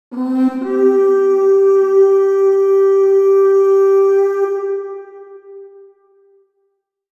Now, next time you post your bug report, conveniently pluck your battle horn from its mount and sound the alarm!
War-horn-sound.mp3